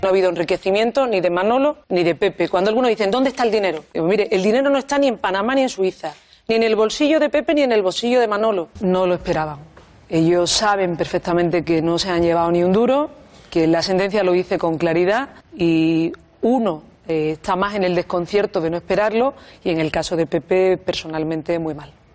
"Pedí perdón y lo pediré las veces que haga falta", ha afirmado en La Sexta en el programa ‘Al Rojo Vivo’ de Antonio Ferreras, donde ha recordado que en el momento de los hechos condenados ella aún no estaba al frente del Ejecutivo andaluz.